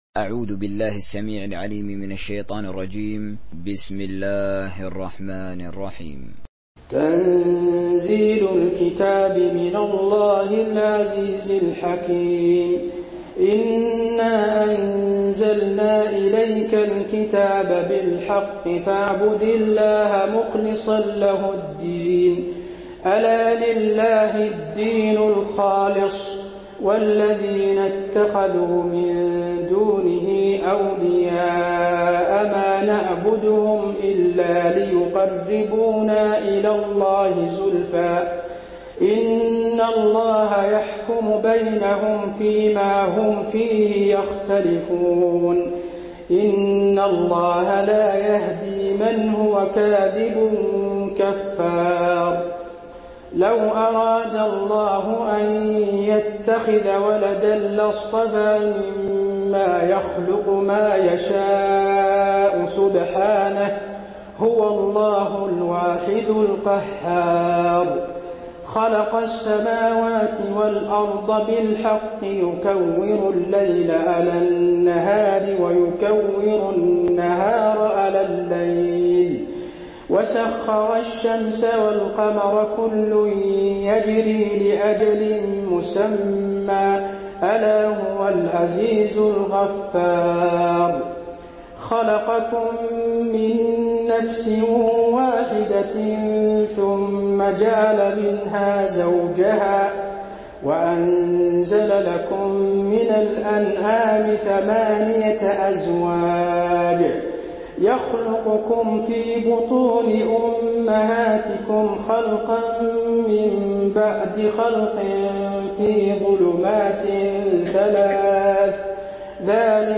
تحميل سورة الزمر mp3 بصوت حسين آل الشيخ تراويح برواية حفص عن عاصم, تحميل استماع القرآن الكريم على الجوال mp3 كاملا بروابط مباشرة وسريعة
تحميل سورة الزمر حسين آل الشيخ تراويح